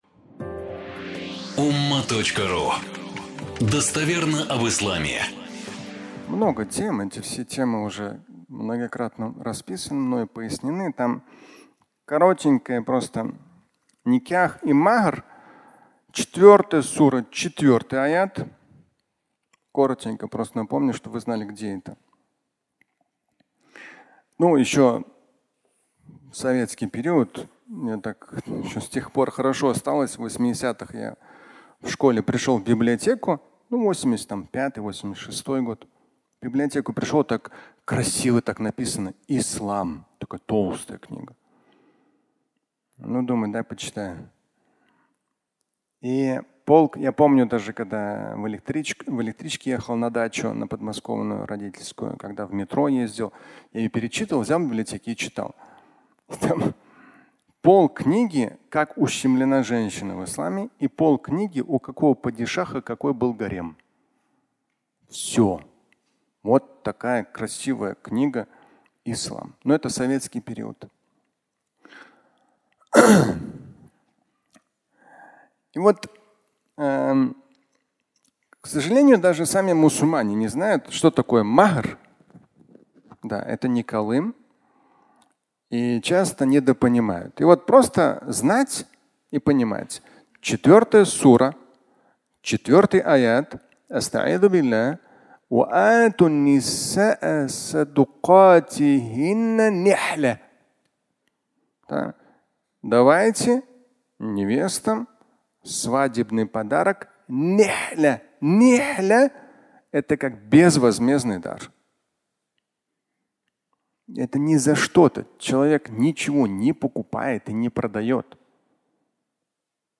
Никях и махр (аудиолекция)